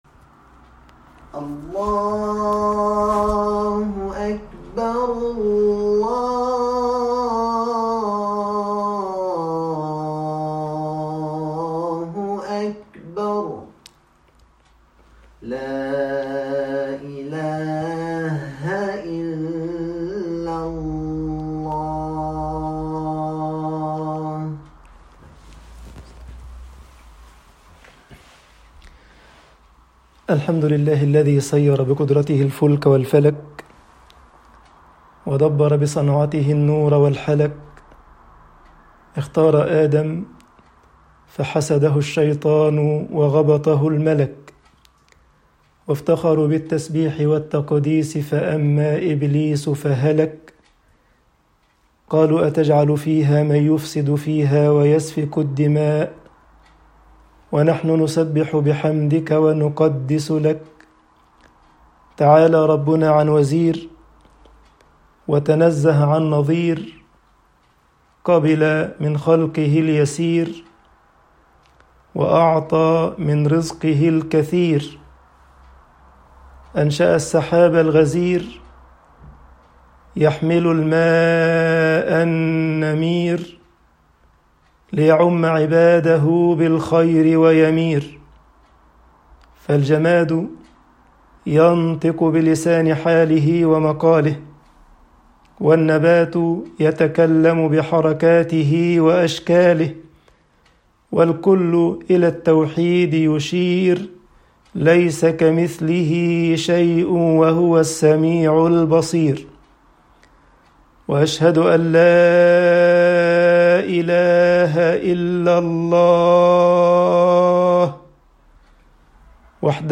خطب الجمعة والعيد